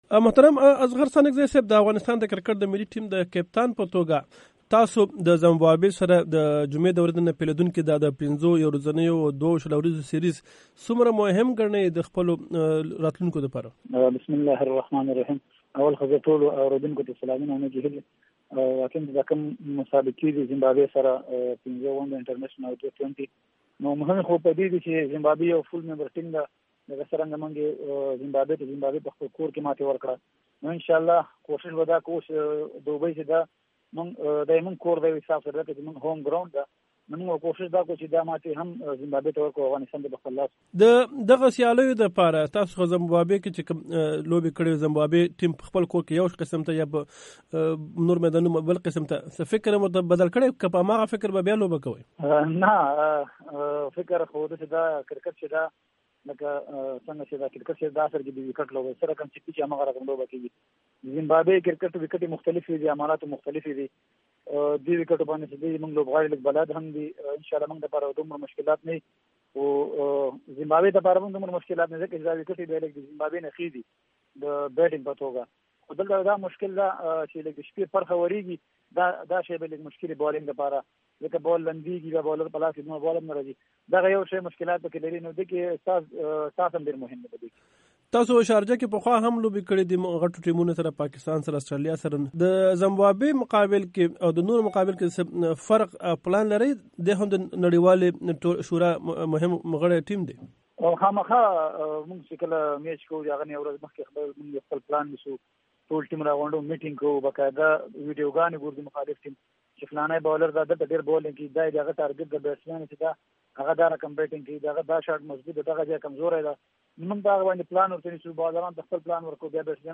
دې سیالۍ دپاره د افغانستان د کرکټ ټیم د تیاریو په اړه د ټیم کپتان اصغر ستانکزي امریکا غږ اشنا راډیو سره مرکه کې ویلي چې دوي د تېرو څو اوونیو نه متحده عربي اماراتو کې د خپلو روزونکو سره په تمرین مصروف دی او د زمبابوې سره د سیالیو دپاره په مکمل ډول تیار دي.